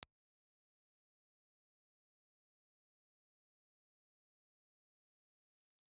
Impulse response of an AEA R88 stereo ribbon microphone.
AEA_R88_IR.wav